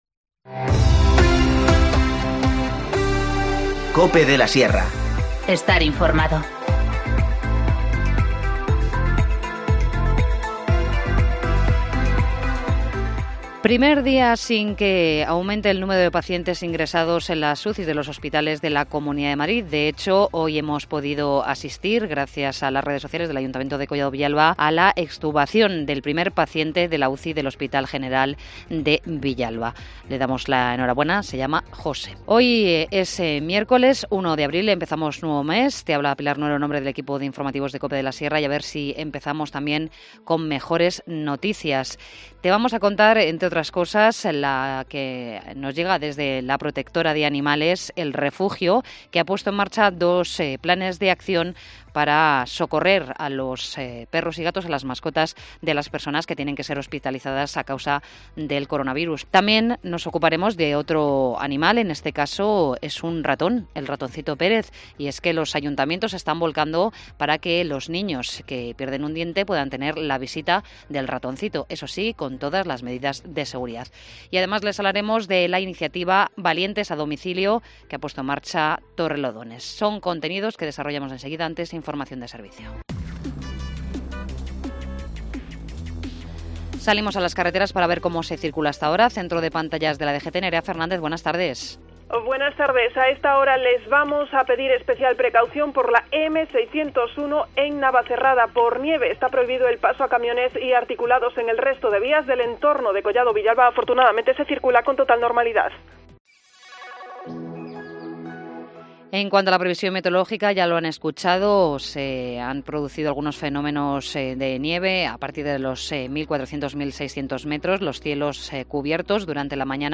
Informativo Mediodía 1 abril 14:20h